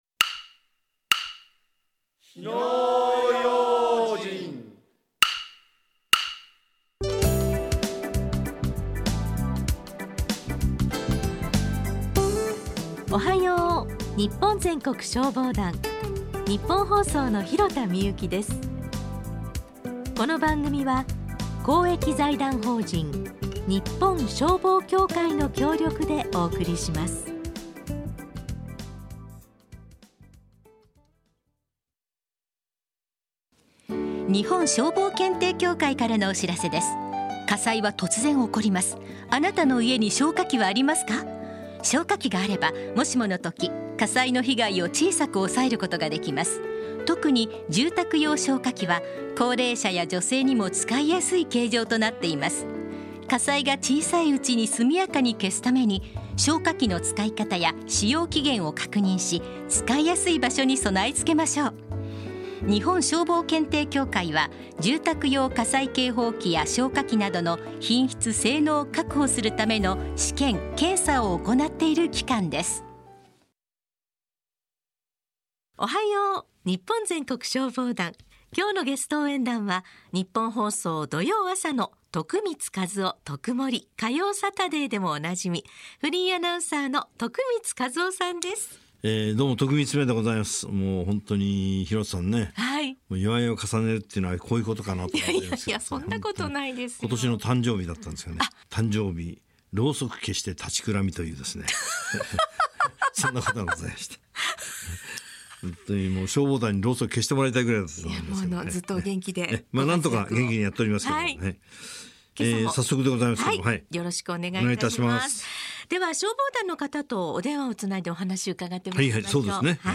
ラジオ放送 - ＴＯＰ - 日本消防協会